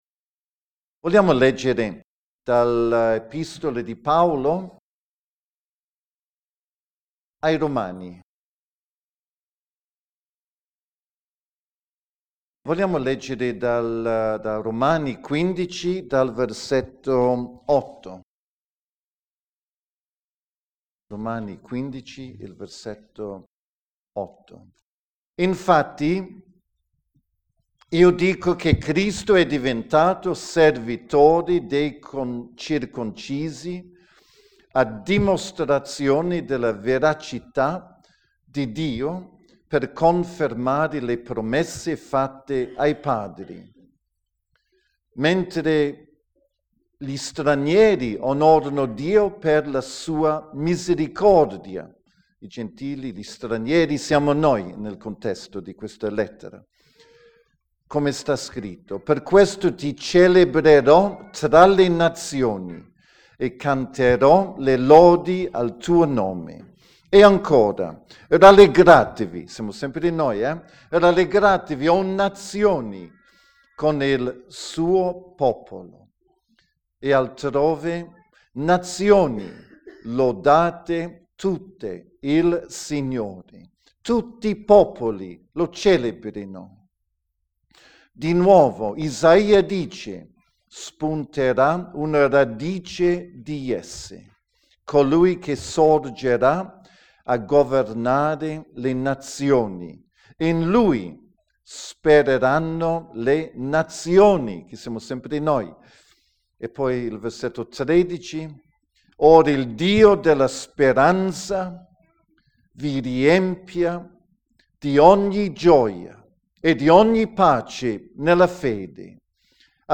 Predicazione 01 maggio 2011 - La gioia del Signore � la mia forza